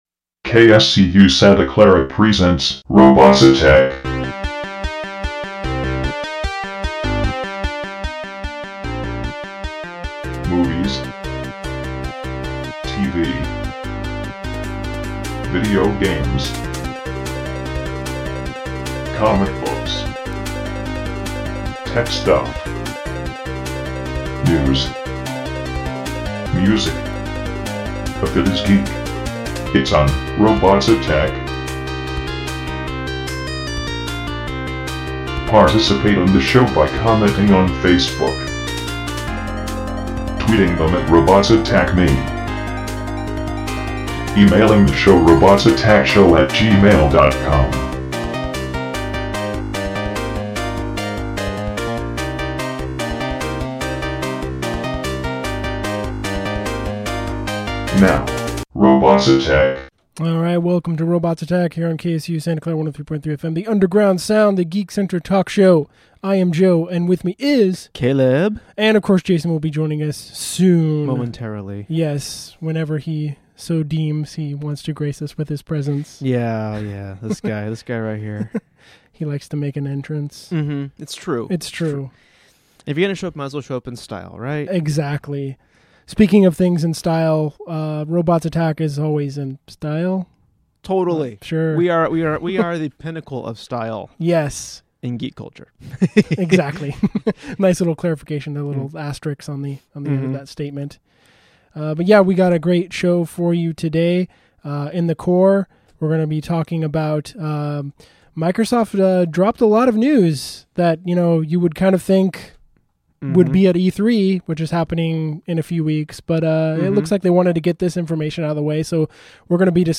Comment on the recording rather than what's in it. Listen live Thursdays from 7pm to 9pm on 103.3 FM KSCU Santa Clara